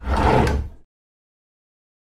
Open Drawer.wav